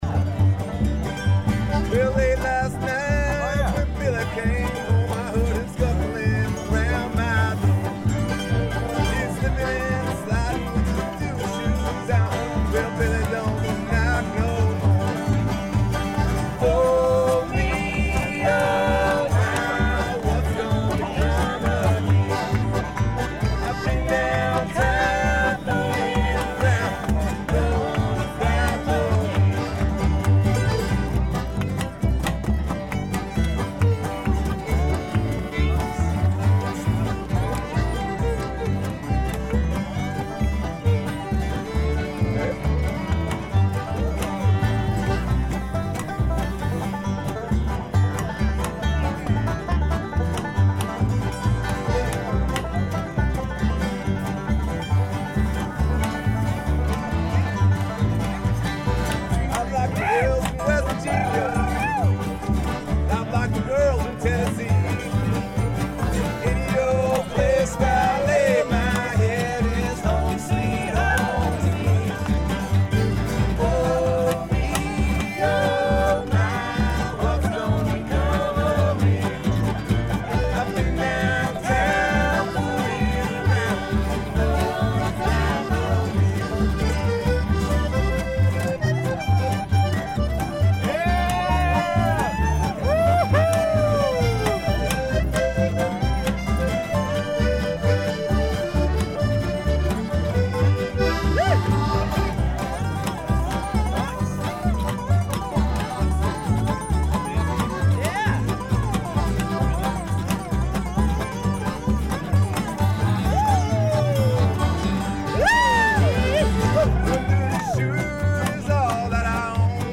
We crossed paths and setup the jam right where we met at the crossroads by the "top of the world" bus.
The crowd began to form and before long we were stirring up folk roots with the aid of the players in the campground!
and many others on banjo, mandolin, fiddle, guitars, and so on...Please email with any more information you may have to add...